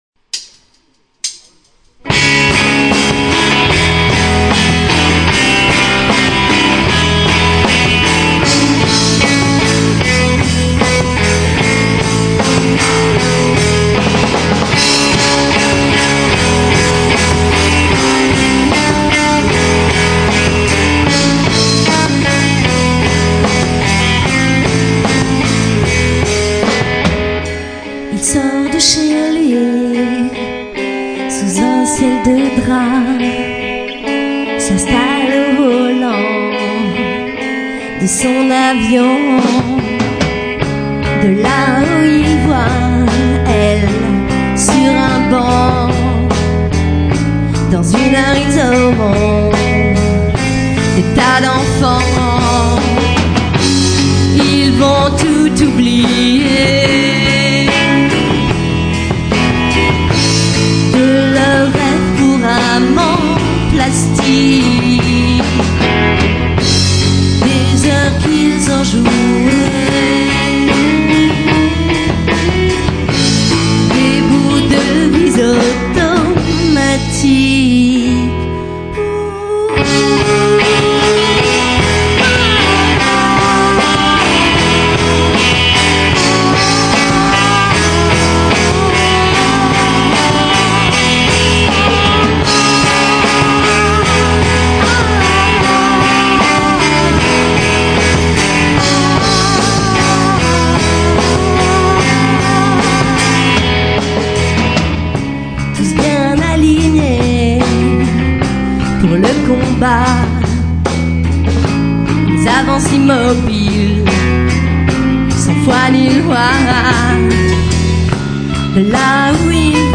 12/02/2005 - L'Espace B